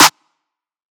clap (snary).wav